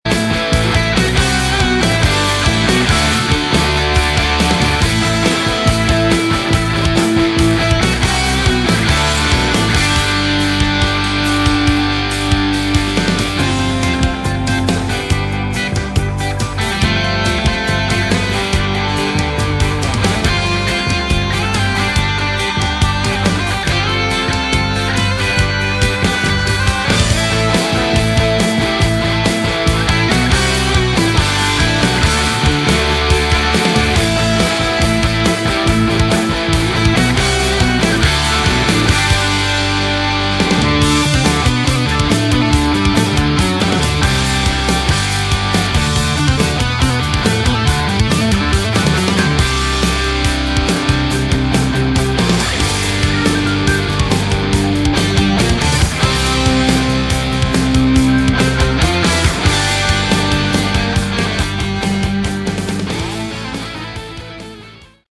Category: AOR / Melodic Rock
vocals
guitars, bass, keys
keys, synths